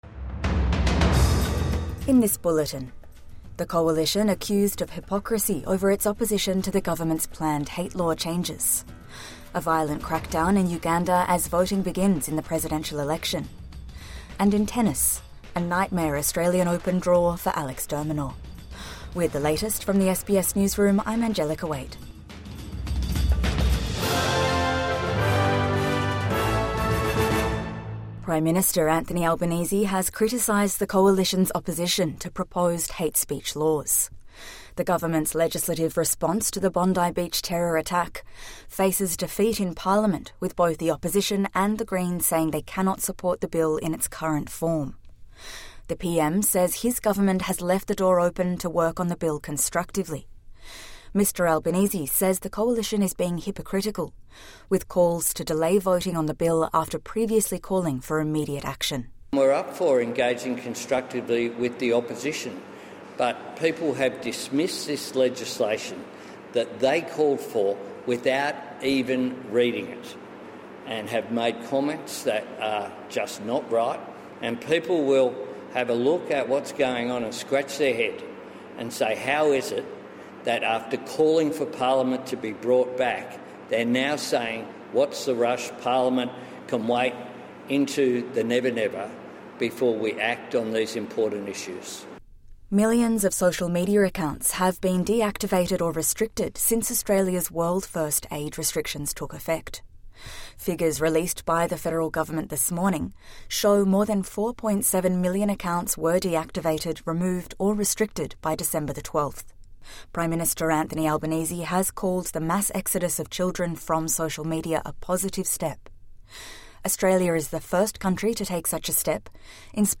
The Coalition accused of hypocrisy over hate law changes | Morning News Bulletin 16 January 2026